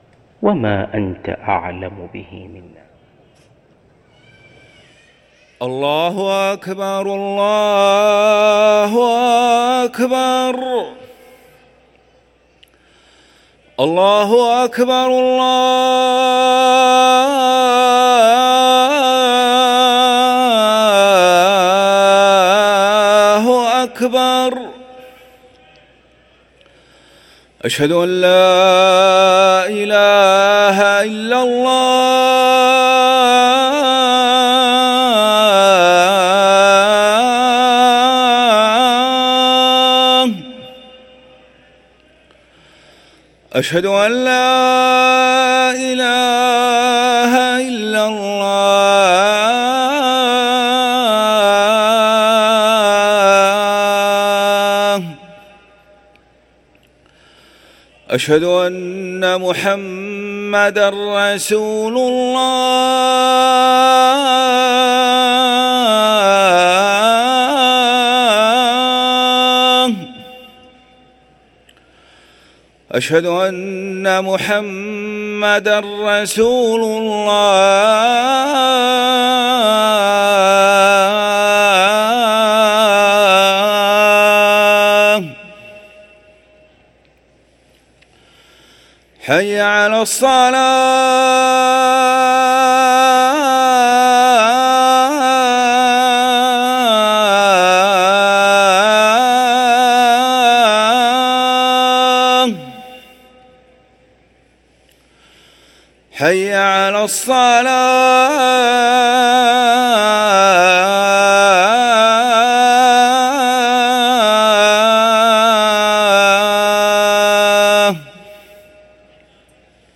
أذان الظهر
ركن الأذان